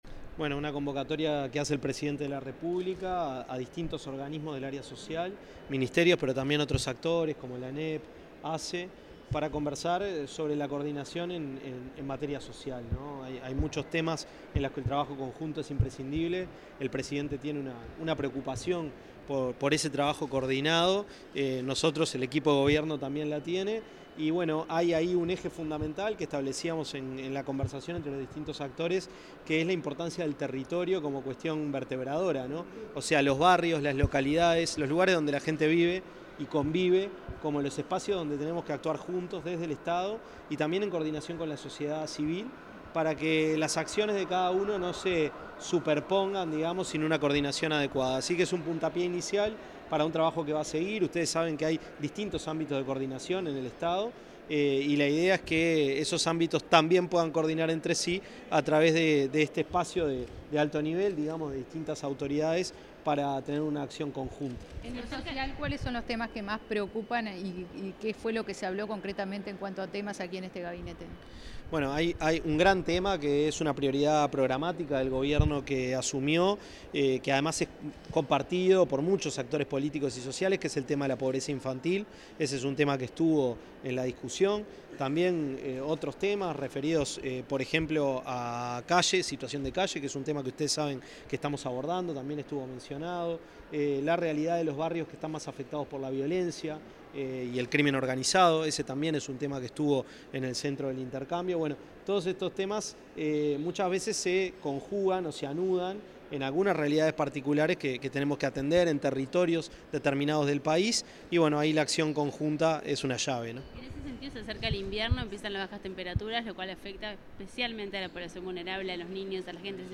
Declaraciones a la prensa del ministro de Desarrollo Social, Gonzalo Civila
Declaraciones a la prensa del ministro de Desarrollo Social, Gonzalo Civila 26/05/2025 Compartir Facebook X Copiar enlace WhatsApp LinkedIn Tras una reunión entre el presidente de la República, profesor Yamandú Orsi, y jerarcas de ministerios y organismos involucrados en las políticas sociales del Gobierno, el ministro de Desarrollo Social, Gonzalo Civila, brindó declaraciones a la prensa, en la Torre Ejecutiva.